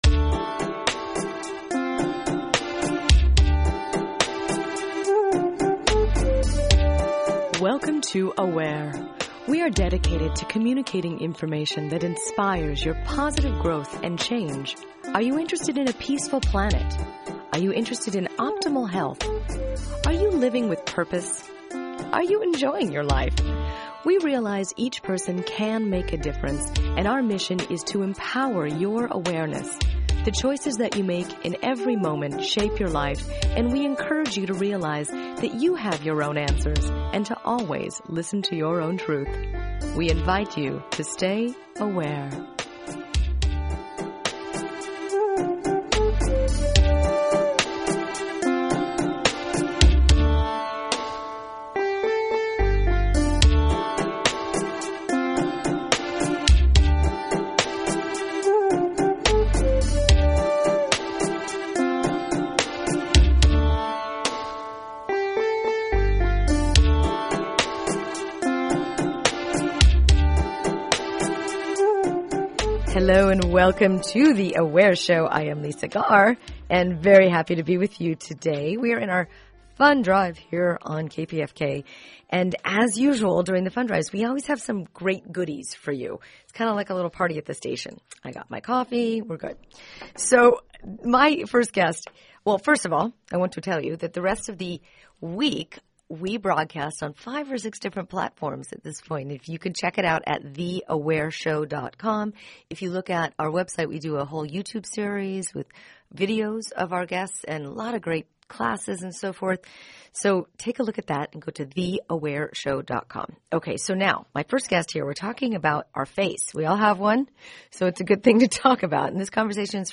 Re-air